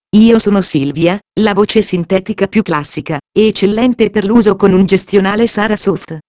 E' la pronuncia di messaggi con voce umana sintetica, ad esempio "Benvenuto e buon lavoro" all'apertura del programma, "Confermi la cancellazione ?" in caso di richiesta di cancellazione di un dato da un archivio, eccetera.
testo pronunciato con la voce "Silvia"
esempio-silvia.wav